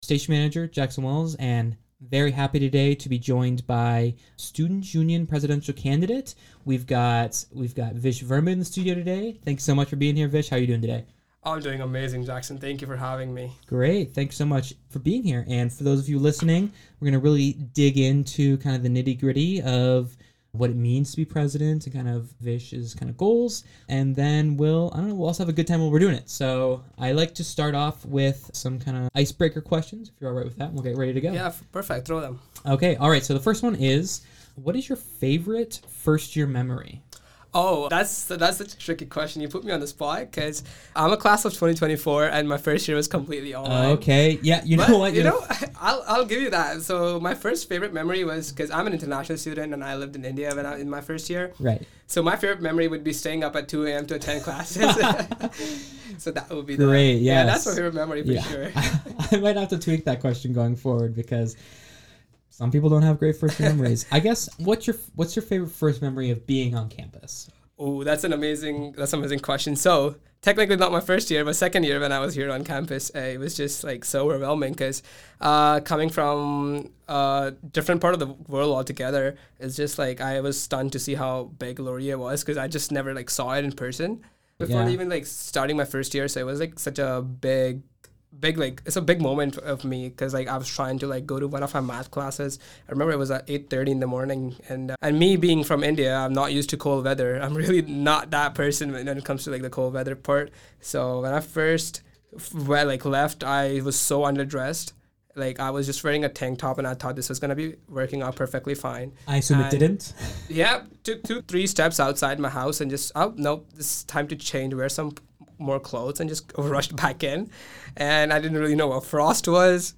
Radio Laurier's interview with Students Union Presidential candidate